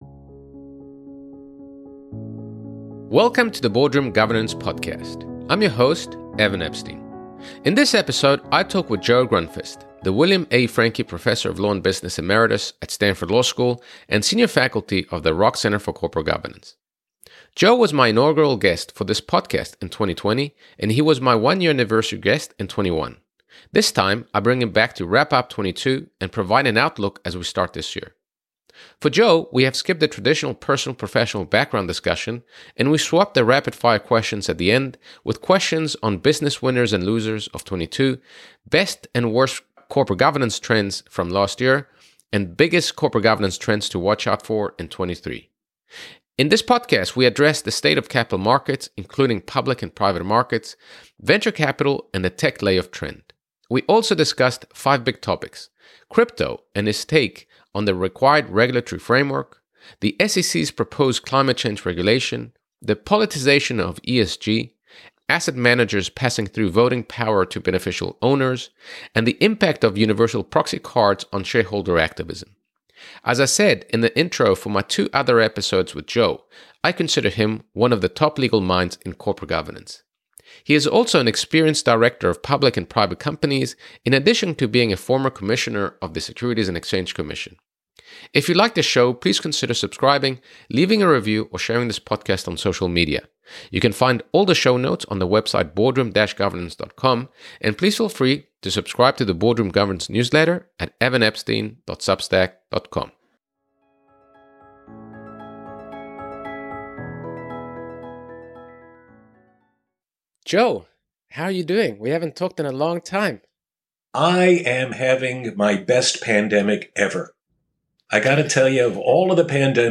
Start of interview.